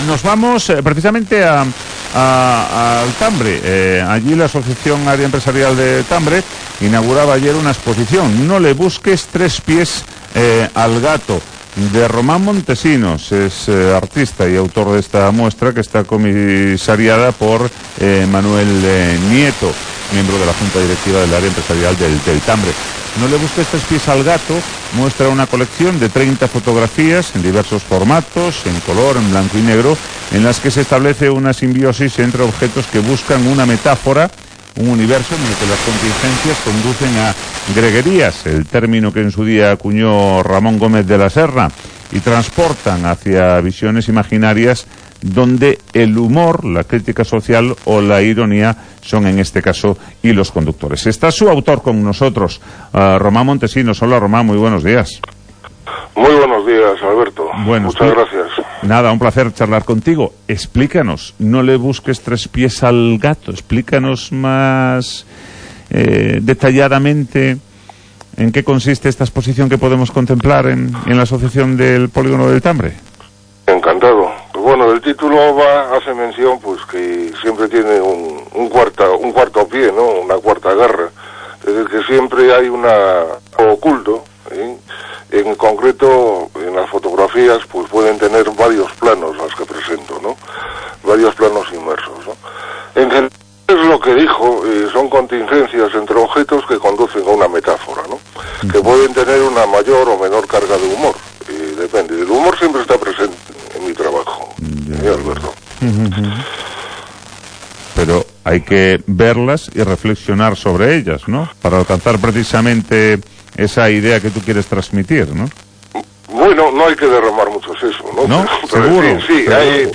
AUDIO: entrevista en Radio Voz